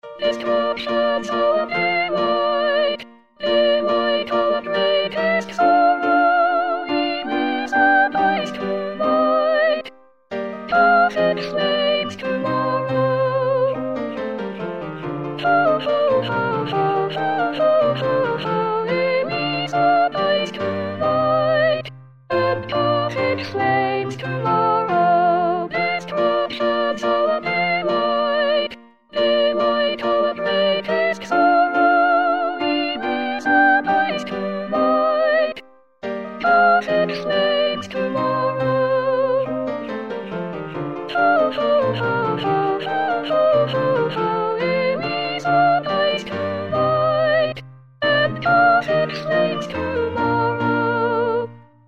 Soprano
33-destructions-our-delight_Soprano.mp3